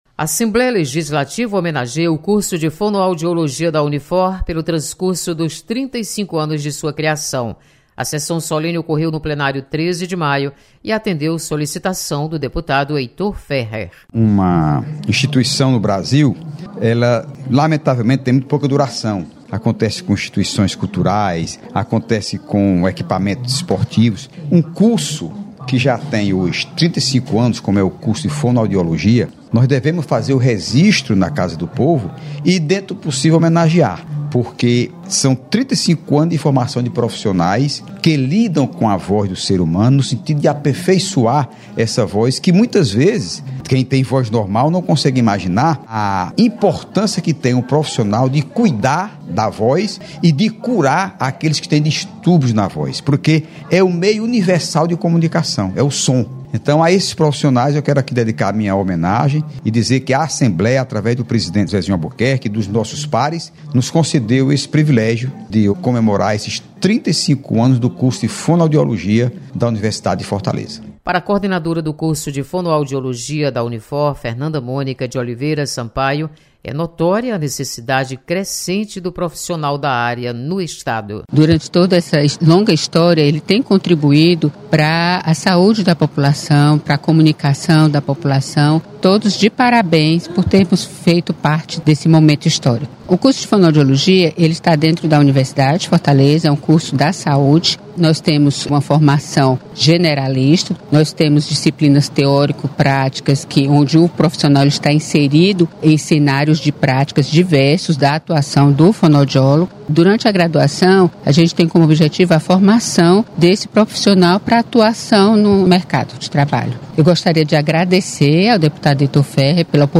Assembleia homenageia Curso de Fonoaudiologia. Repórter